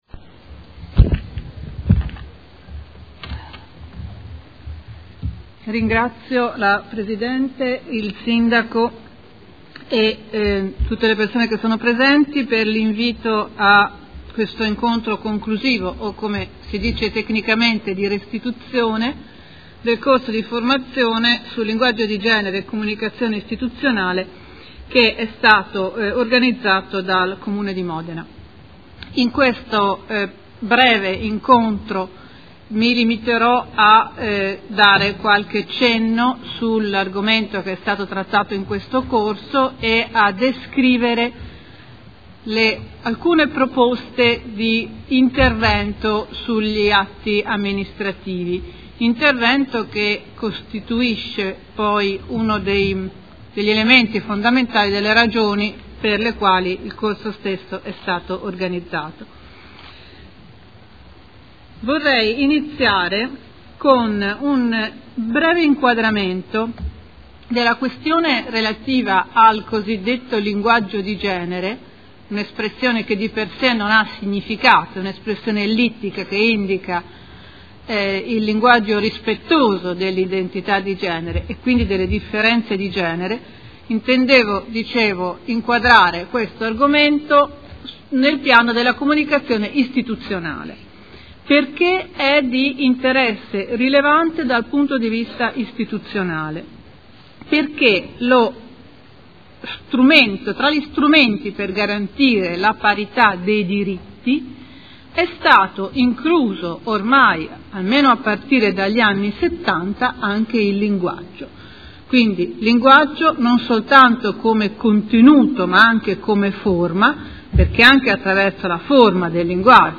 Sito Audio Consiglio Comunale
Lectio Magistralis